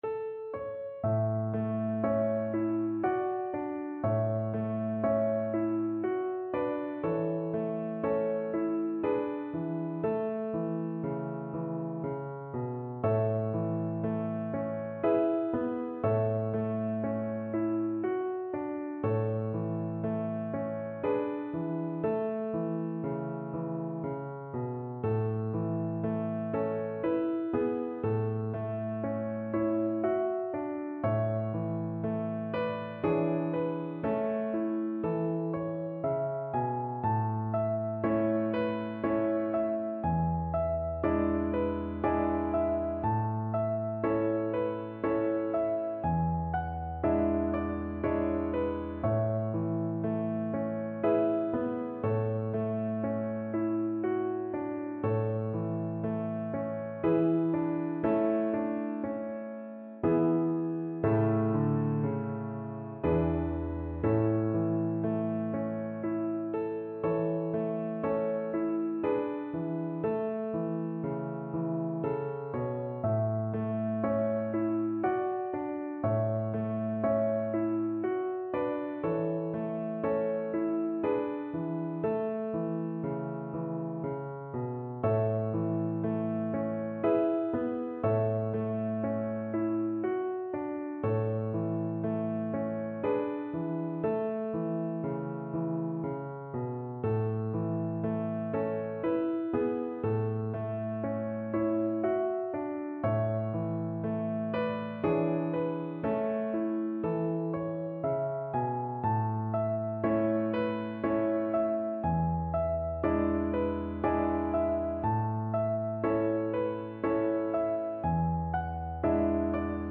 Violin
Traditional Music of unknown author.
A major (Sounding Pitch) (View more A major Music for Violin )
Andante
3/4 (View more 3/4 Music)
E5-E6
Easy Level: Recommended for Beginners with some playing experience